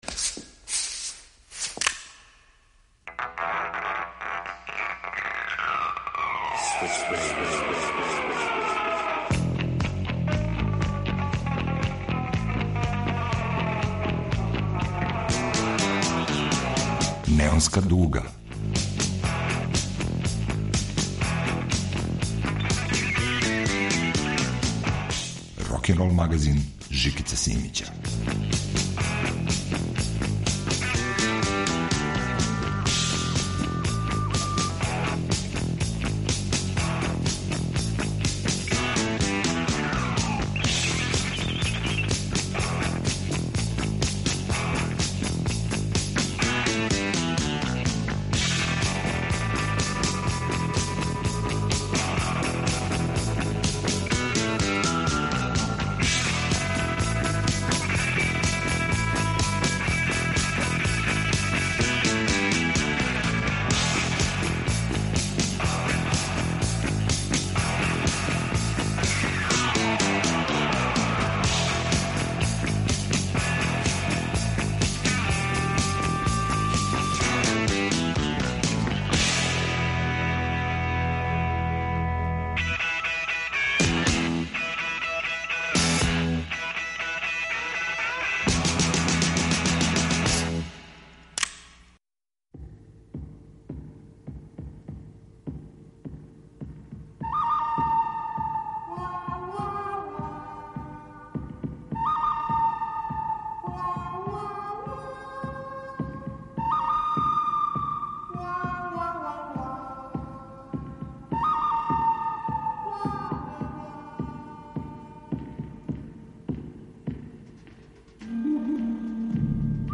Рокенрол као музички скор за живот на дивљој страни.
Ове недеље на репертоару је више од 25 песама - на различитим језицима и необичним инструментима, а са истом музичком визијом.